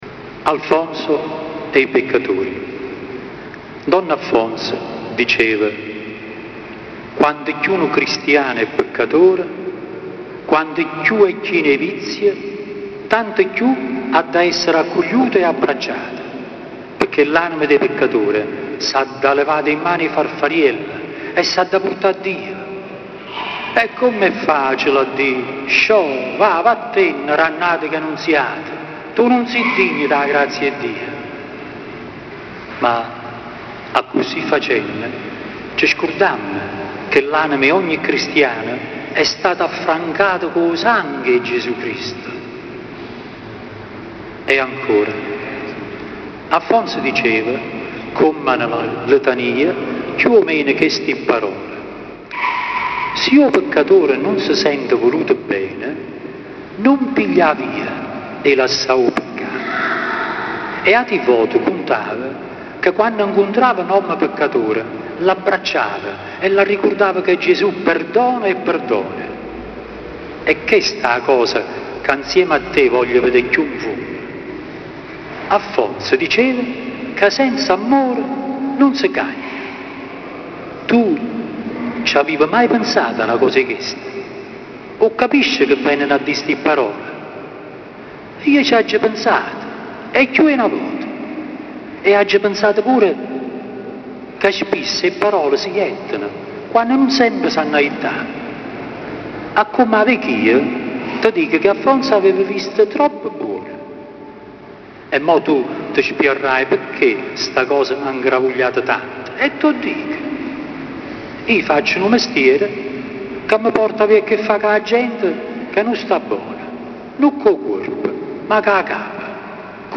dialetto napoletano
Lettura di alcuni brani
(la registrazione è stata fatta dal vivo con… una macchina fotografica digitale)